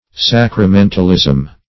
Search Result for " sacramentalism" : The Collaborative International Dictionary of English v.0.48: Sacramentalism \Sac`ra*men"tal*ism\, n. The doctrine and use of sacraments; attachment of excessive importance to sacraments.